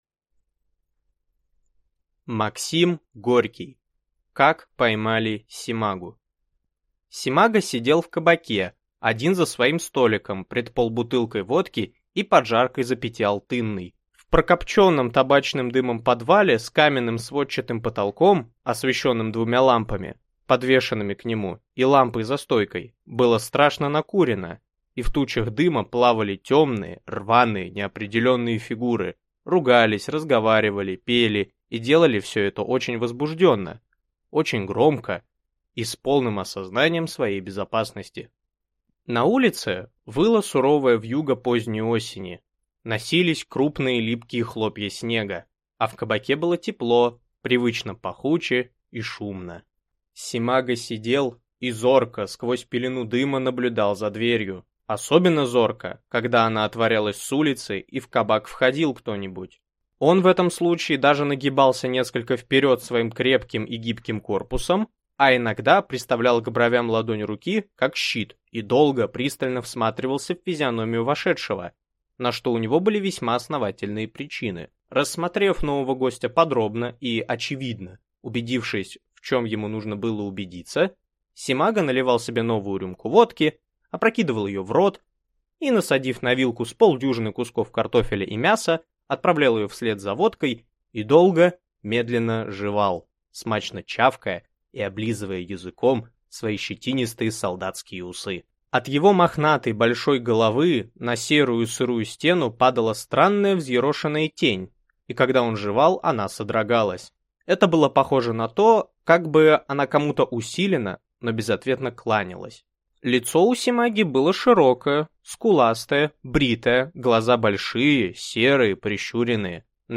Аудиокнига Как поймали Семагу | Библиотека аудиокниг